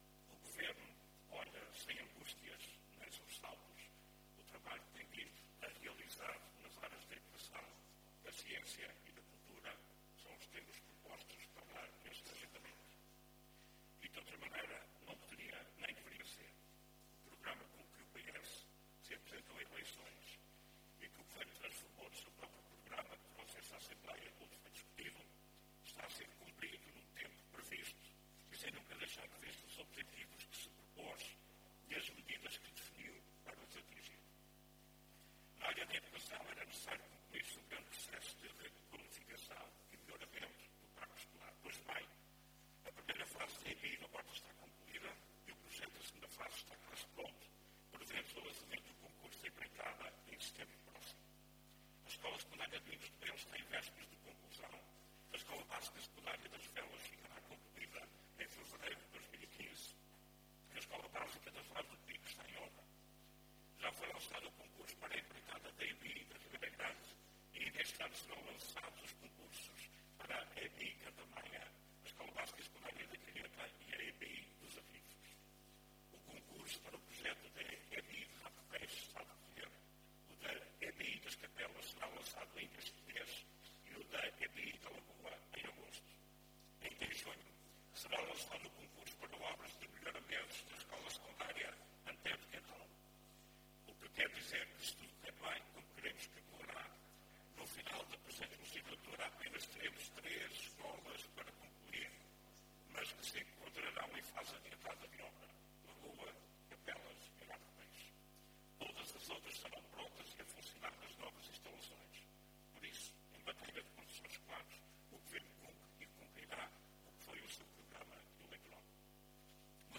O Governo dos Açores olha “sem angústias nem sobressaltos” o trabalho que tem vindo a realizar nas áreas da Educação, Ciência e Cultura, afirmou hoje, na Horta, Luiz Fagundes Duarte.
O Secretário Regional da Educação, Ciência e Cultura, que falava na Assembleia Legislativa durante uma interpelação ao Governo, garantiu que, na área da educação, no que diz respeito à requalificação e melhoramento do parque escolar, “o Governo cumpre e cumprirá o que foi o seu programa eleitoral”.